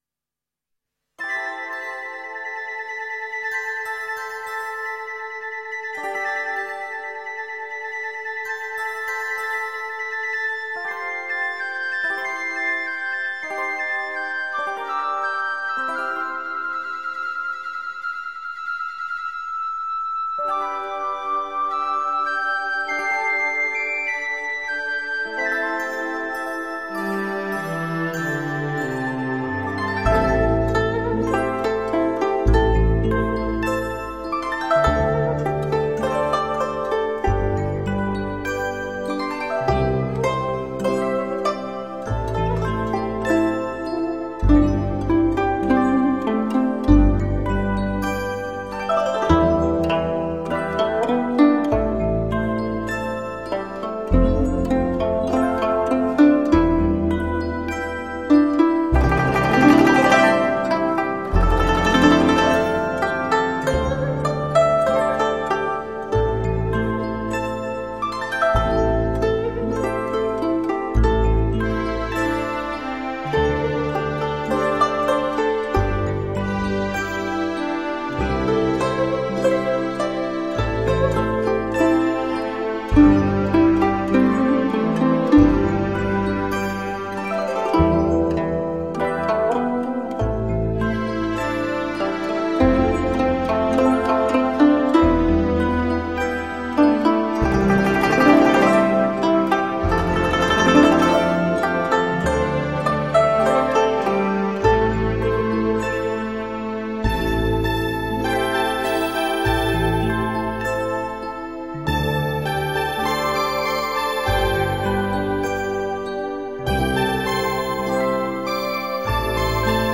佛音 诵经 佛教音乐 返回列表 上一篇： 水在瓶(菩薩偈